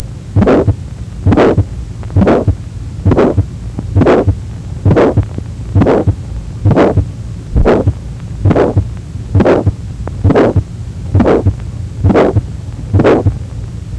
เสียงหัวใจ (Heart sound)